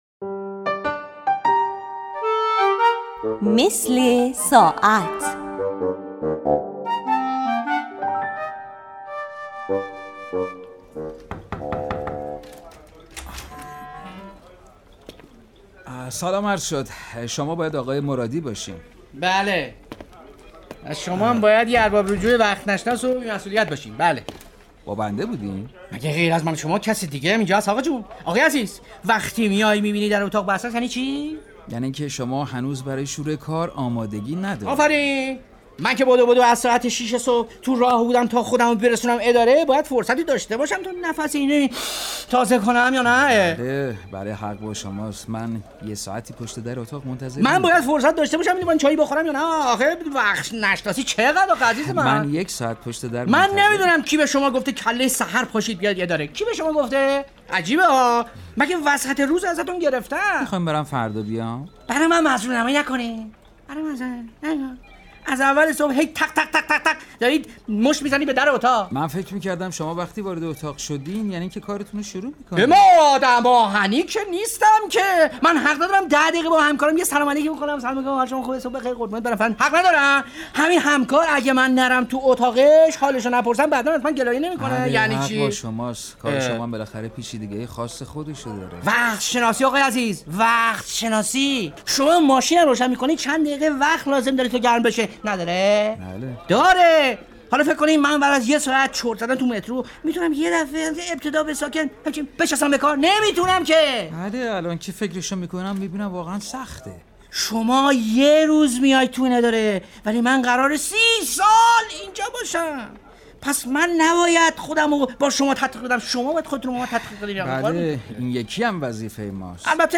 به گزارش خبرنگار مهر، نمایش رادیویی «مثل ساعت» درباره ارباب رجوعی است که برای انجام یک کار اداری وارد سازمانی می شود و زمانیکه بعد از یک ساعت معطلی به اتاق فرد مورد نظر می رود با عصبانیت او مواجه می شود که چرا اجازه نداده است دقایقی برای خودش استراحت کند.